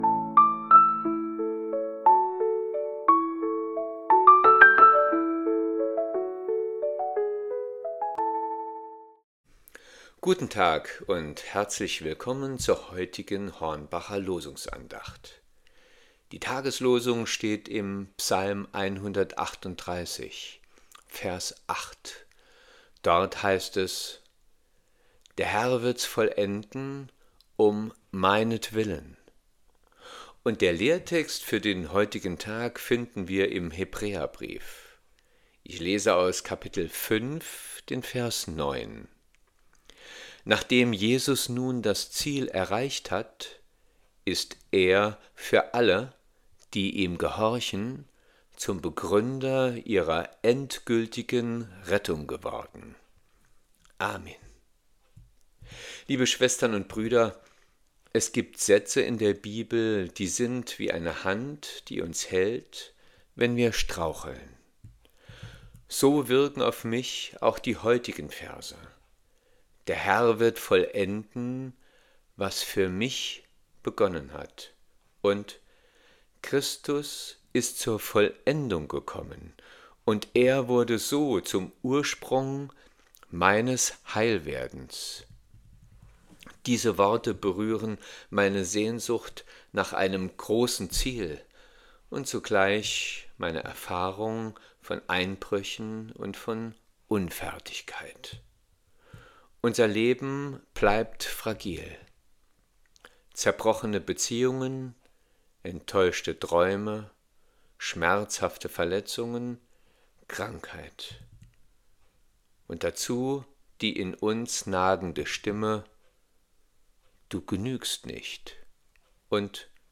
Losungsandacht für Dienstag, 25.11.2025
Losungsandachten